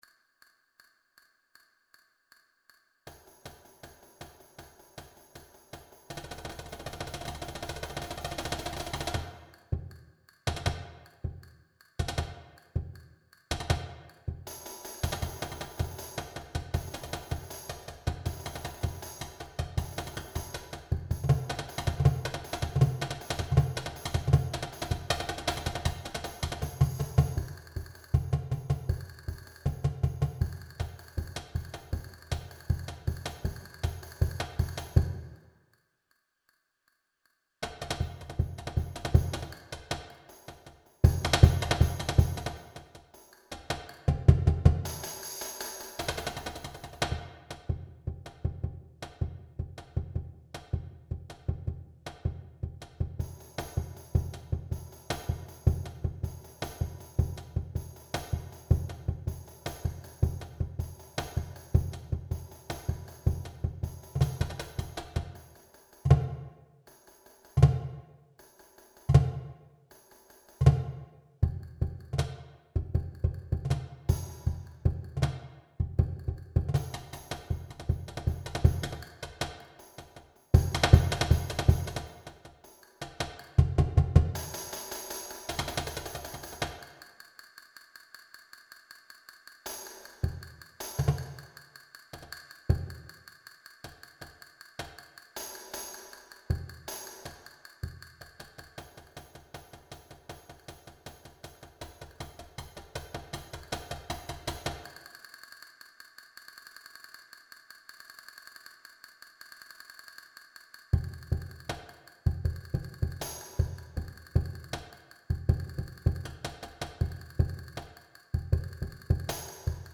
Genre: Percussion Ensemble
# of Players: 4